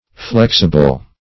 Flexible \Flex"i*ble\, a. [L. flexibilis: cf. F. flexible.]